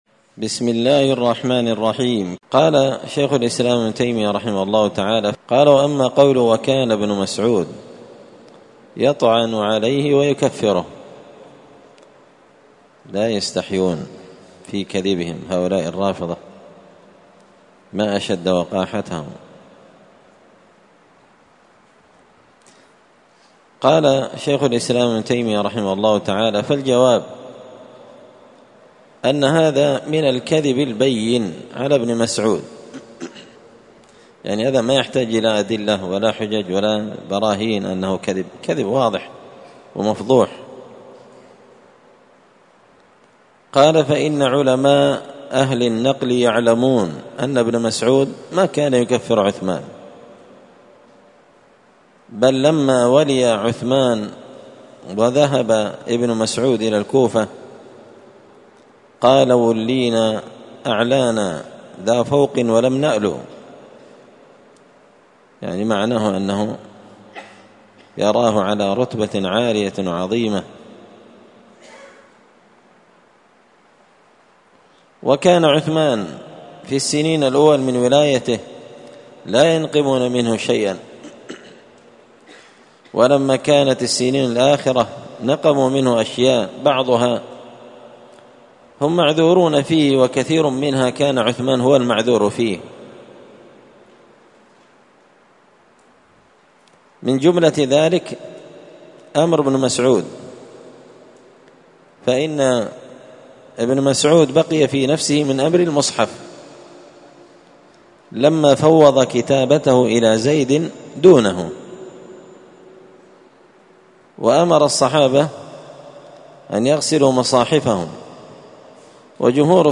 الدرس الثالث والثلاثون بعد المائة (133) تابع فصل في طعن الرافضي على عثمان والرد عليه
مسجد الفرقان قشن_المهرة_اليمن